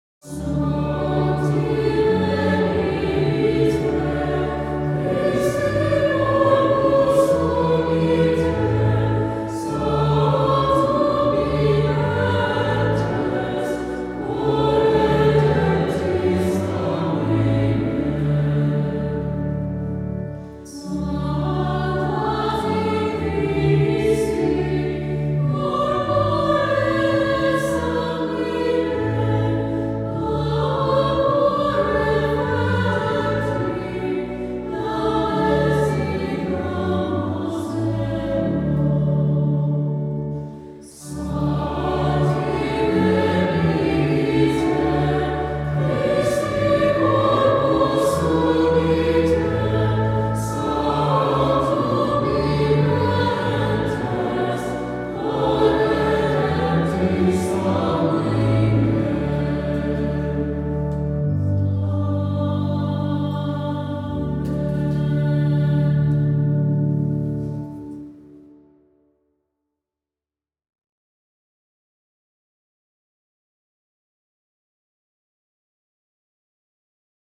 Brebeuf Hymn Index
Organ Only; Voices Removed • Temporary Recording • Midi Soprano • Midi Alto • Midi Tenor • Midi Bass